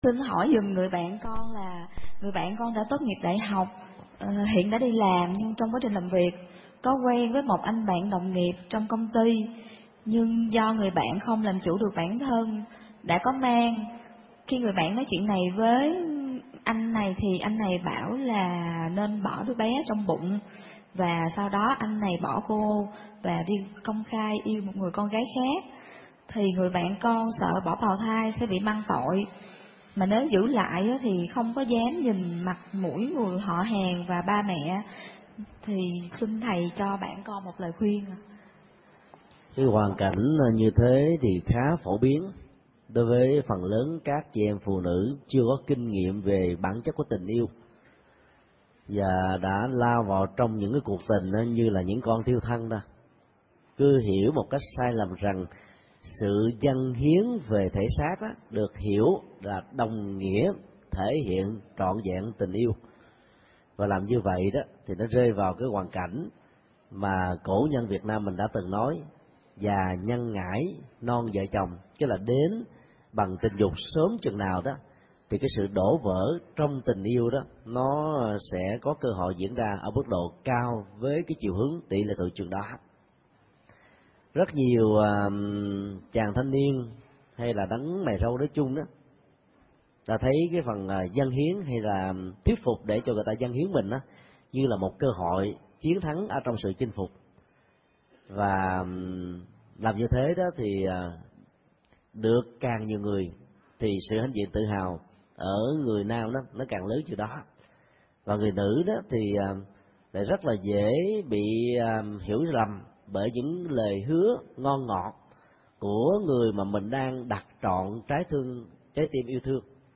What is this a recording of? Vấn đáp: Ứng xử hoàn cảnh người yêu phụ tình và ép phá thai